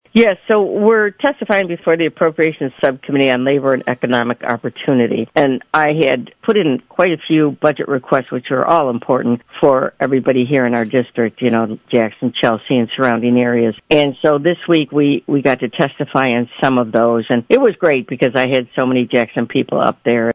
Jackson, Mich. (WKHM) — State Representative Kathy Schmaltz has recently been testifying in favor of multiple Jackson County entities in request for grant funding from the state in the House’s new process where representatives make their case in front of a committee. Rep. Schmaltz discussed that process during her latest appearance on A.M. Jackson.
This full conversation is available to listen to and download on the local interviews portion of our website.